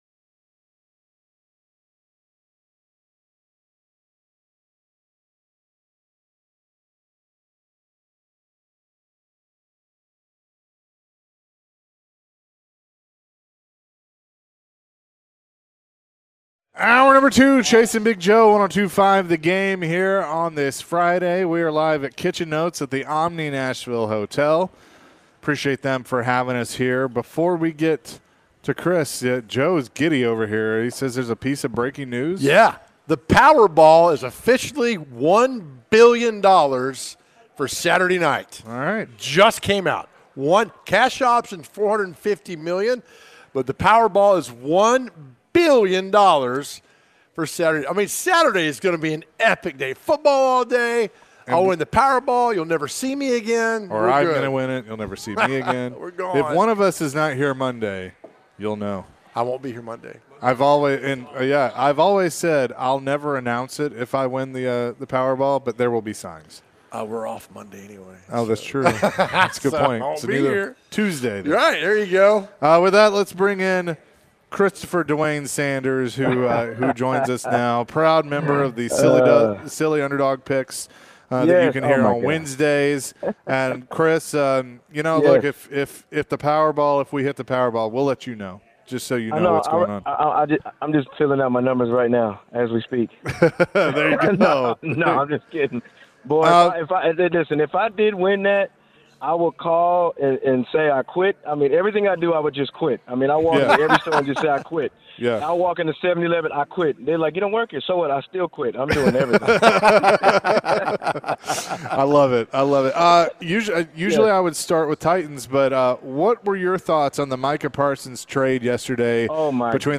Forever Titans WR Chris Sanders joined the show discussing college football and the Titans upcoming season. What does Chris think about the upcoming college football season?